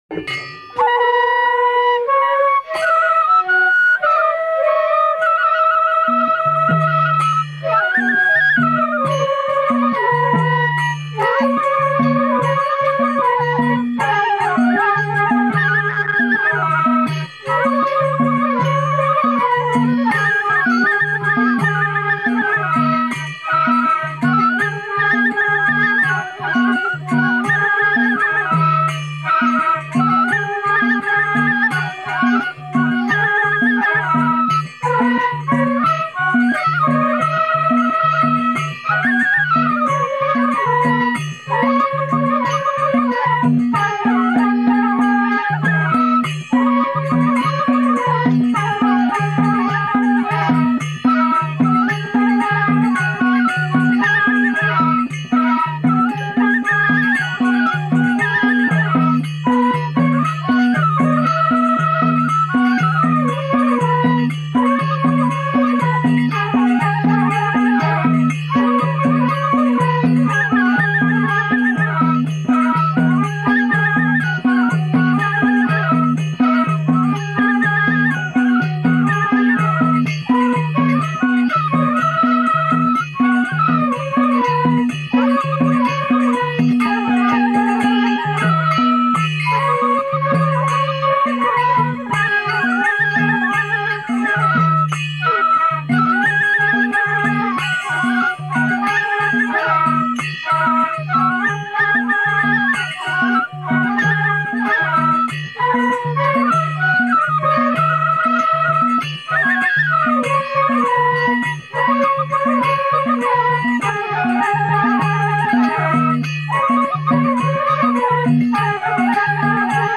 Marsi Malasri (música newa)